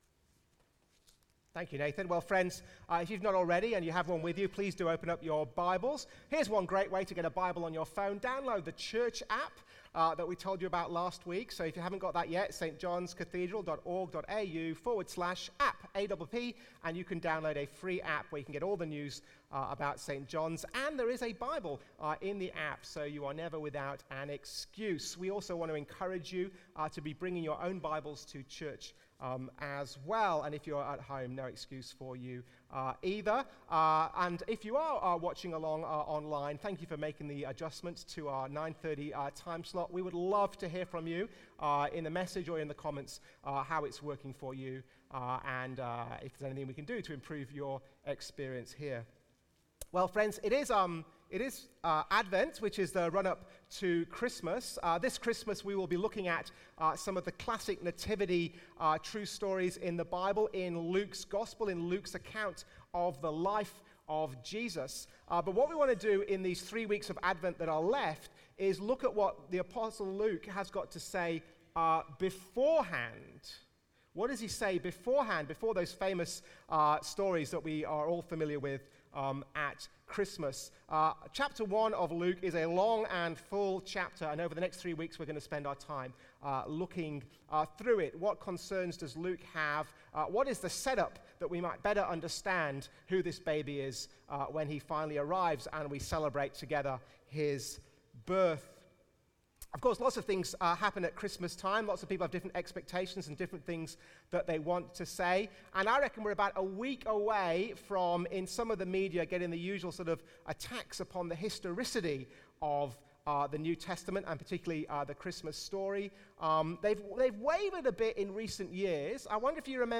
Sunday sermon
from St John’s Anglican Cathedral Parramatta.